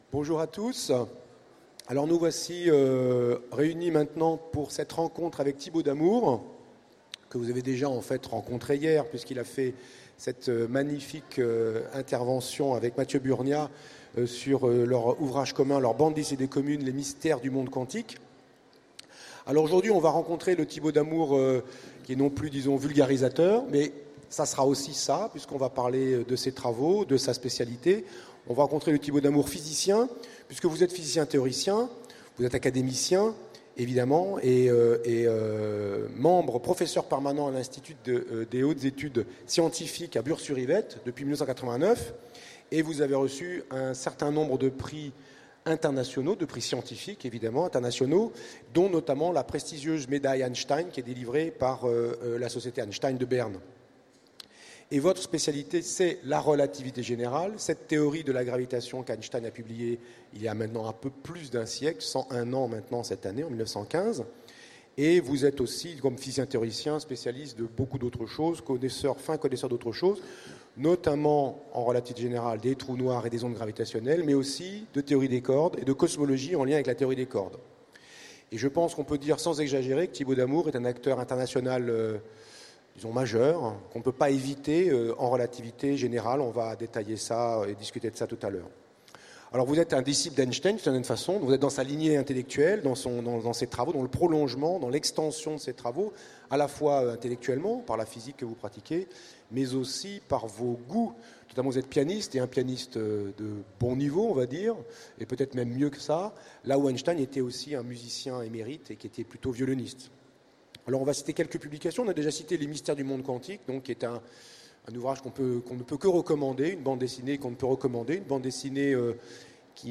- le 31/10/2017 Partager Commenter Utopiales 2016 : Rencontre avec Thibault Damour Télécharger le MP3 à lire aussi Thibault Damour Genres / Mots-clés Rencontre avec un auteur Conférence Partager cet article